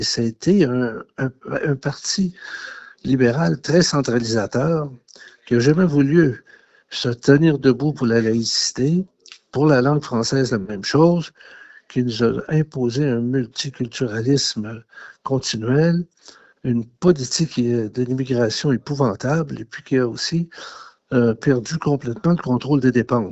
En entrevue, le député de Bécancour-Nicolet-Saurel, a expliqué que son remplaçant aura beaucoup de travail devant lui.